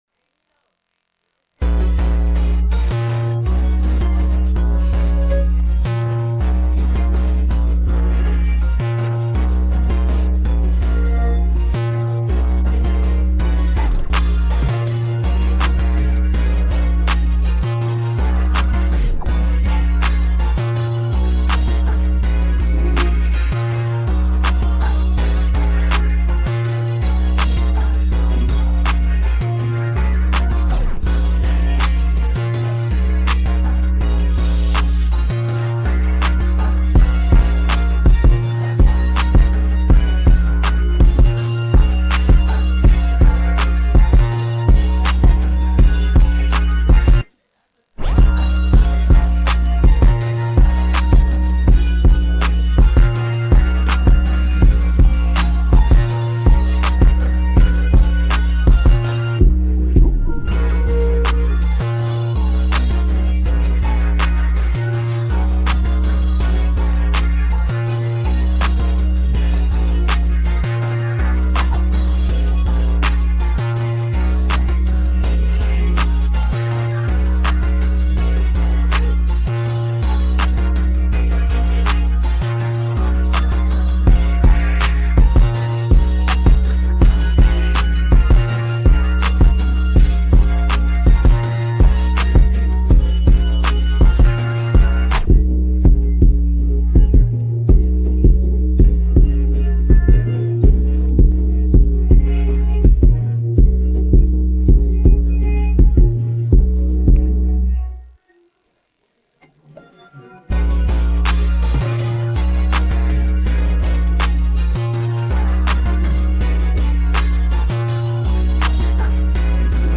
on August 25, 2022 in Rap Instrumental Archives